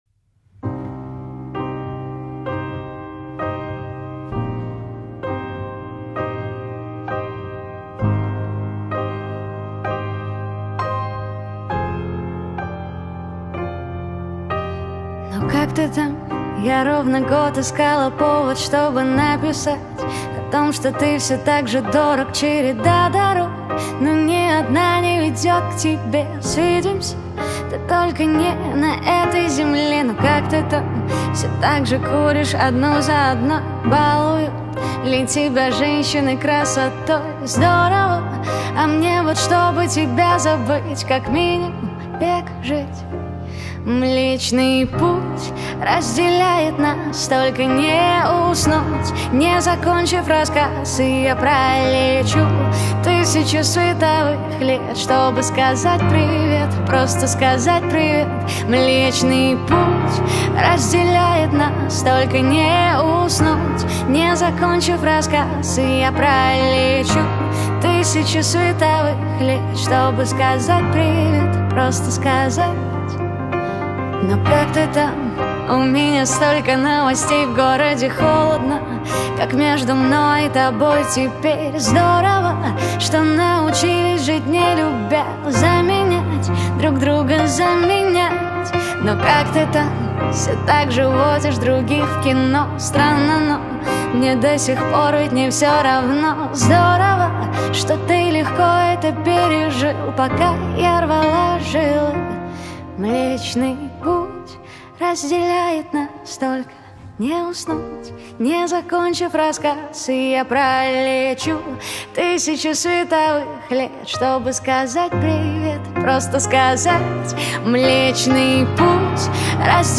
(Акустика)